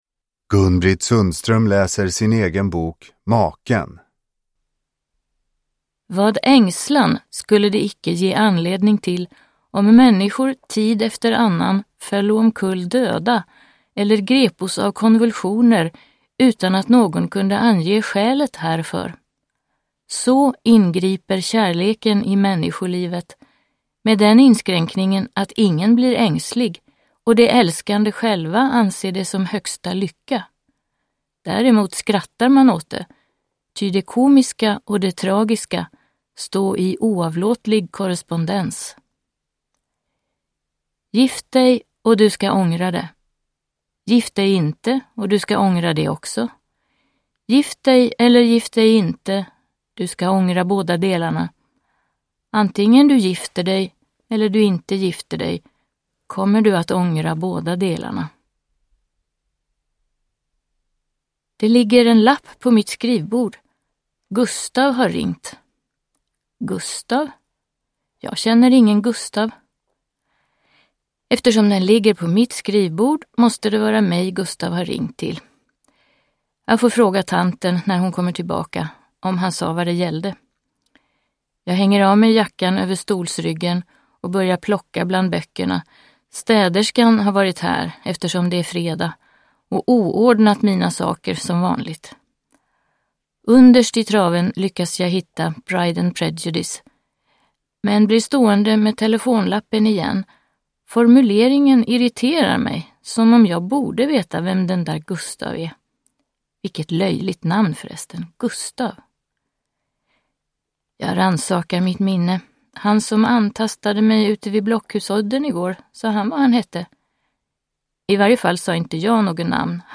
Uppläsare: Gun-Britt Sundström
Ljudbok